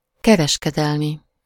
Ääntäminen
Synonyymit commerçant mercantile Ääntäminen France Tuntematon aksentti: IPA: /kɔ.mɛʁ.sjal/ Haettu sana löytyi näillä lähdekielillä: ranska Käännös Ääninäyte 1. kereskedelmi Suku: m .